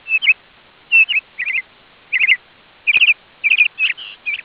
BIRD_I.wav